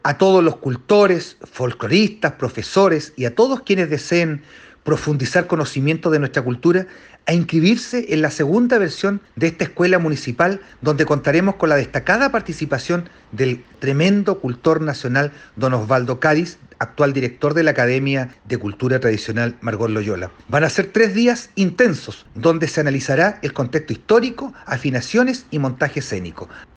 CUÑA-ALCALDE-2DA-ESCUELA-CULTURA-TRADICIONAL.mp3